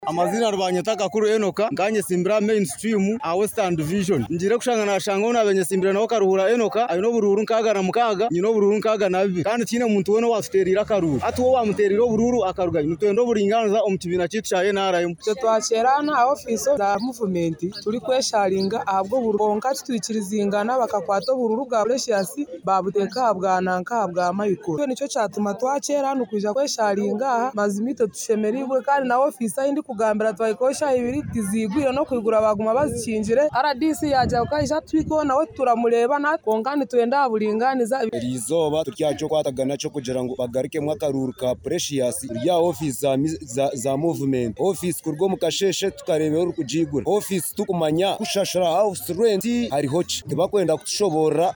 VOXPOP-.mp3